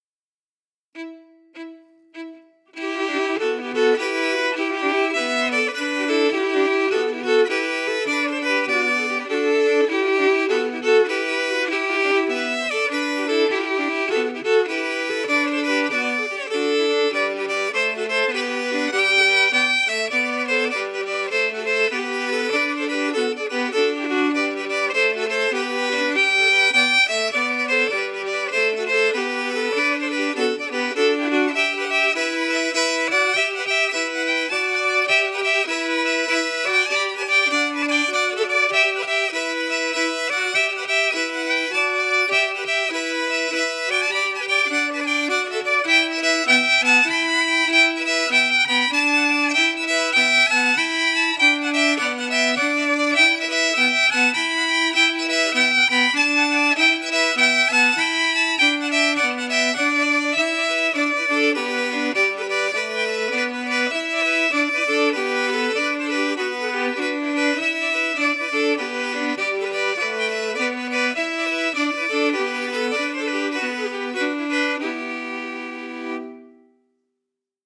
Key: Em
Form: slip Jig
Harmony emphasis
M: 9/8
Genre/Style: Irish slip jig